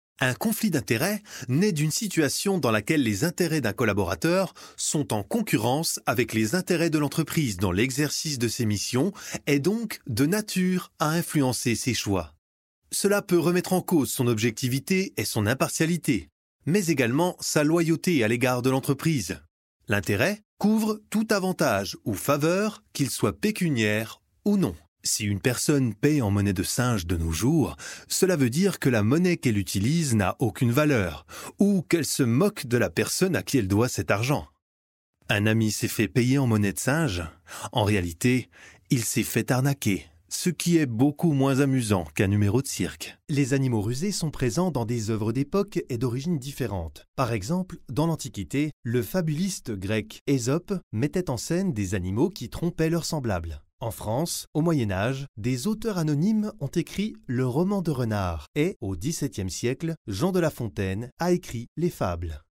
E-learning
I am a professional french voicer over from 5 years with a smooth young voice, with some pretty bass; i can easily add modulations on my voice.
Isolated Cabin